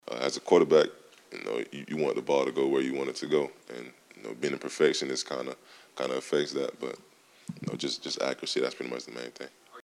Anthony Richardson, the quarterback for the Florida Gators, talked in a press conference Monday about how he is working on better communication within his team and his own accuracy moving forward throughout the season.